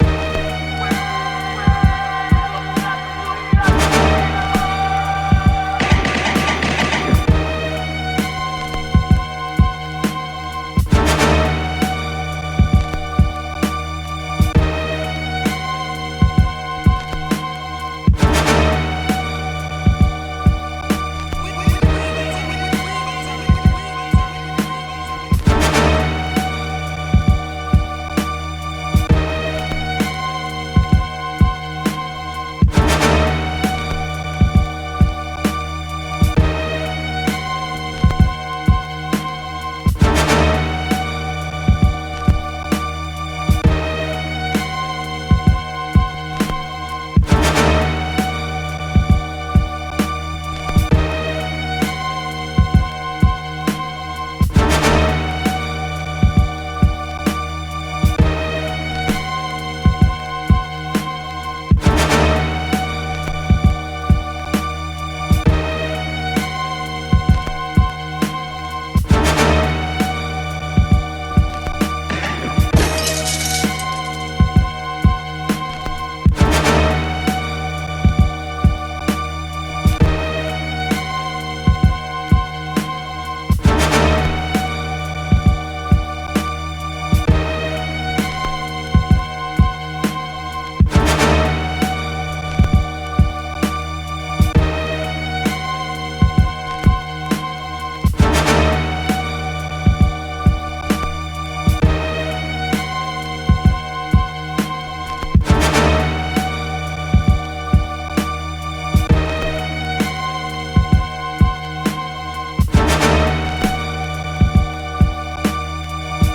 Boom Bap Instrumentals